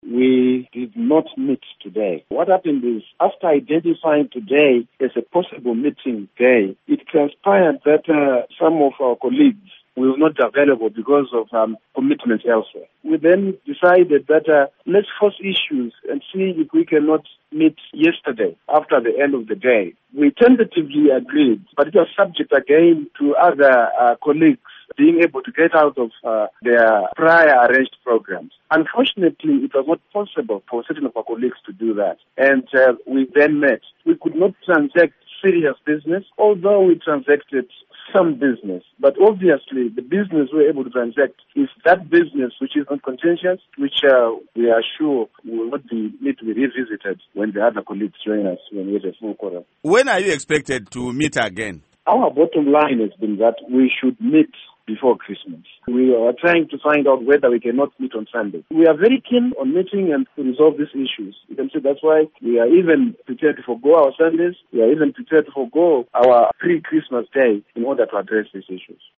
Interview With Minister Eric Matinenga